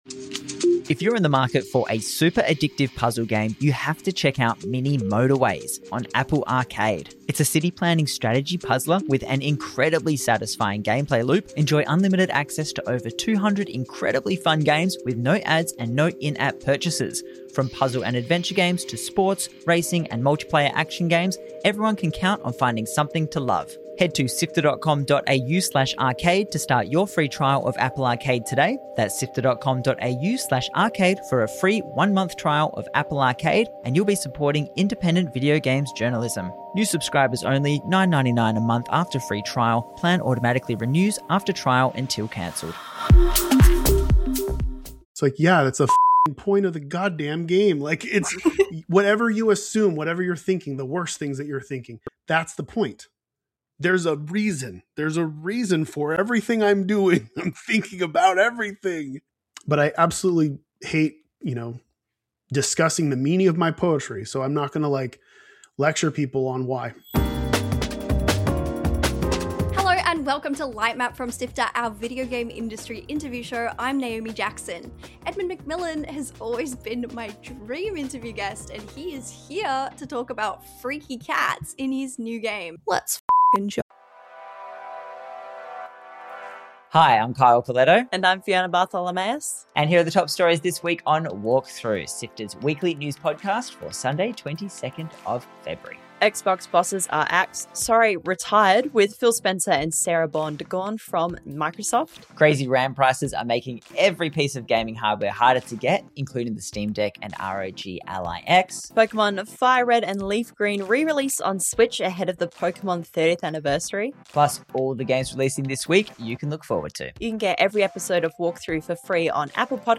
With piles and piles of cats fed into the mill to defeat your enemies and possibly solve every problem. Edmund McMillen joins Lightmap for an in depth chat about the cat breeding and battle simulator, plus we talk about the Camdrone Project.